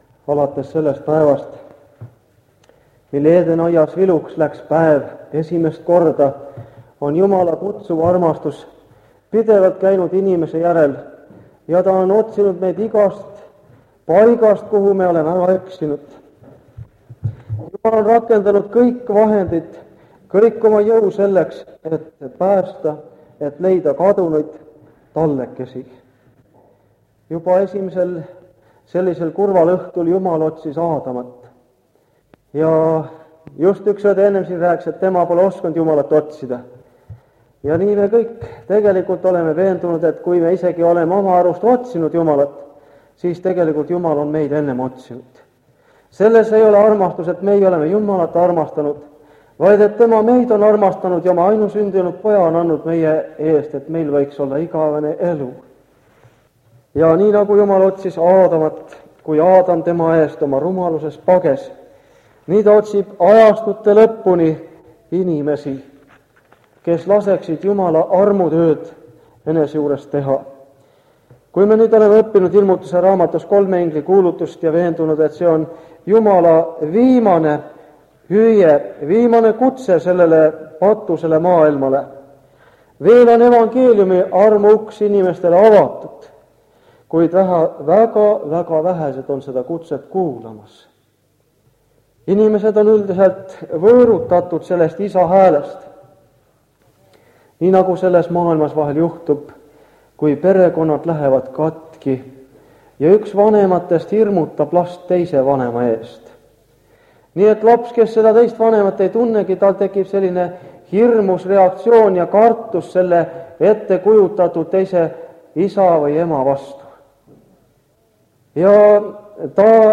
Üks vana jutlus lintmaki lindilt.